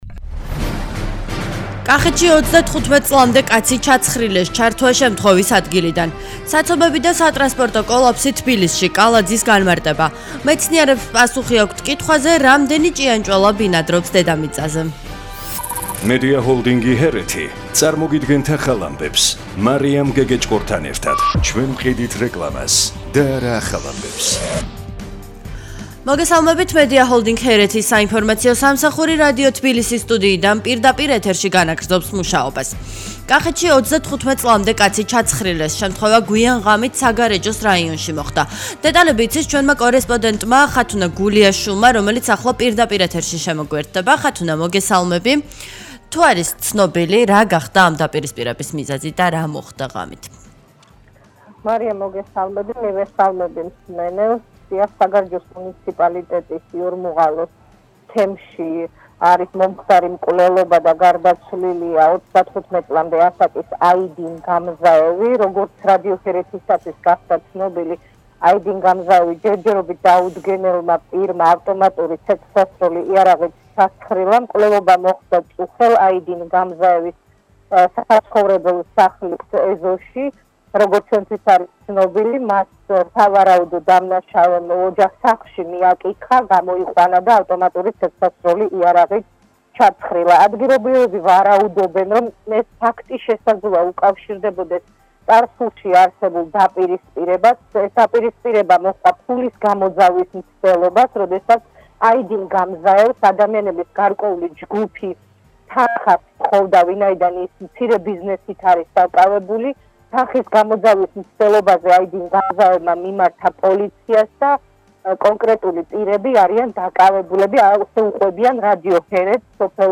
ახალი ამბები 13:00 საათზე
ჩართვა შემთხვევის ადგილიდან